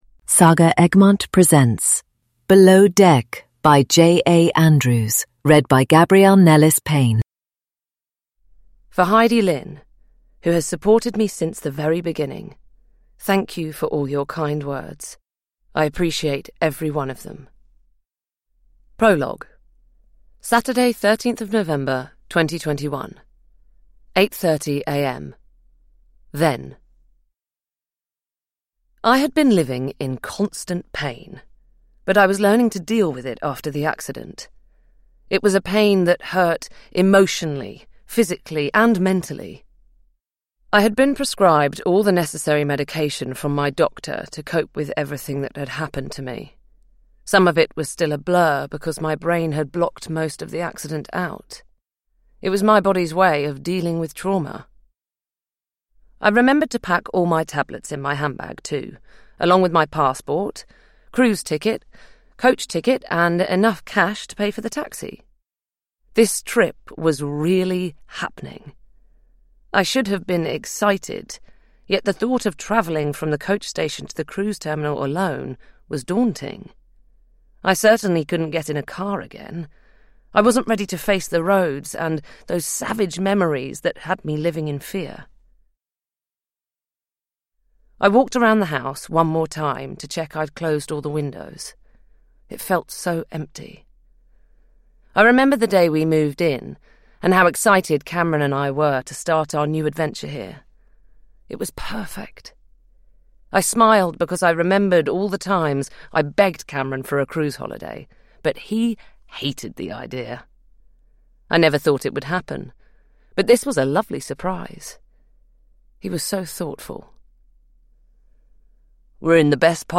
Below Deck: A tense psychological thriller at sea (ljudbok) av J A Andrews